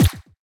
brushTap.ogg